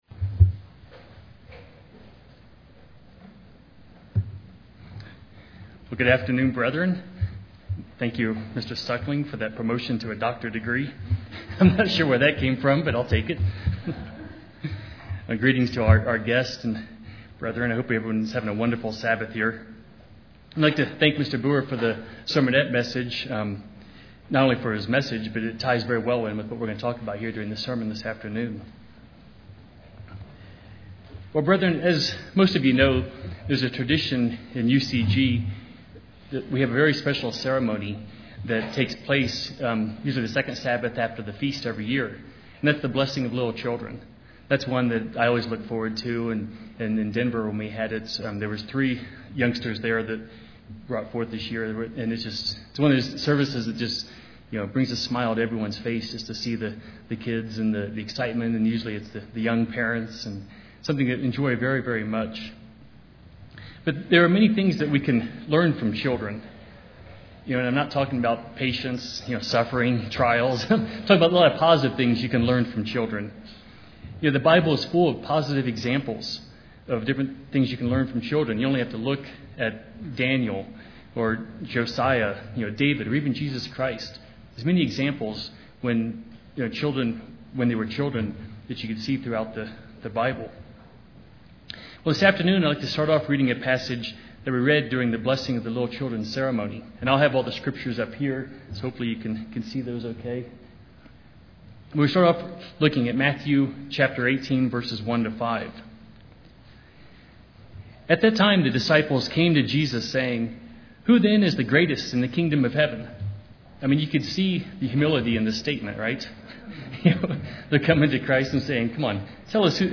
This sermon will address these questions to put us on a path for spiritual growth.